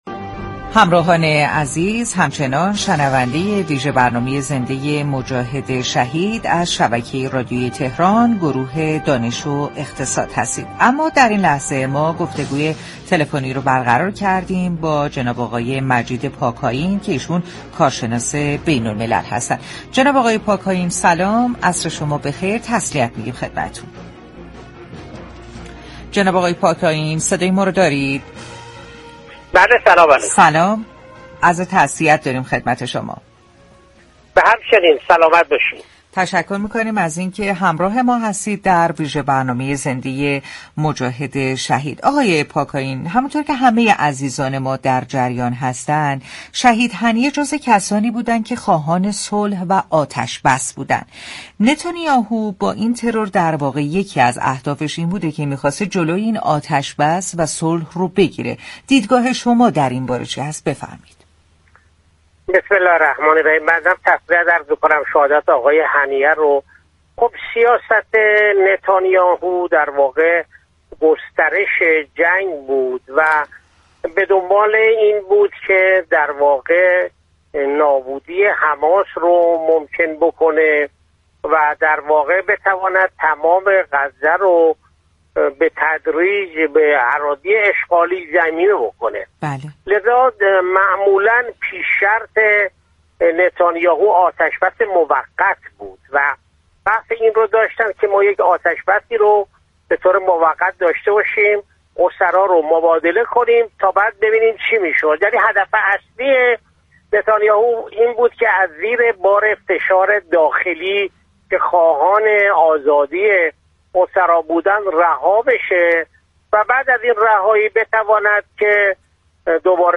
در گفتگو با ویژه برنامه «مجاهد شهید» رادیو تهران